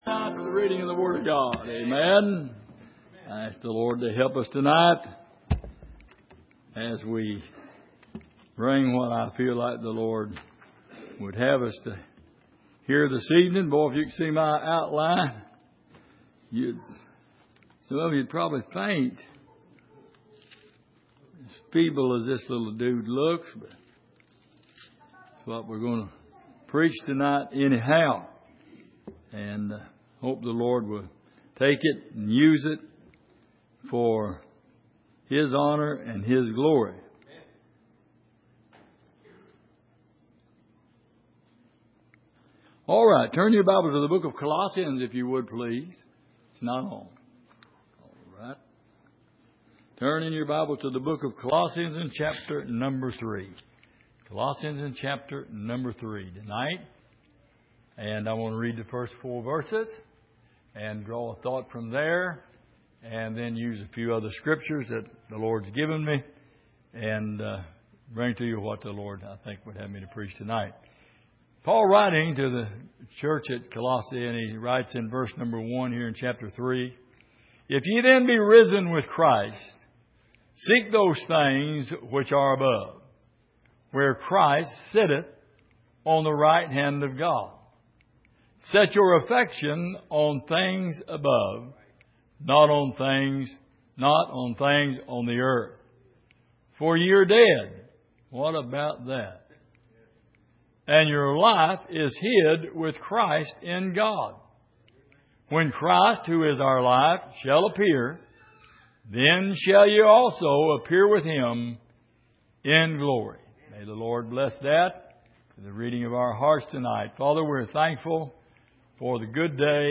Passage: Colossians 3:1-4 Service: Sunday Evening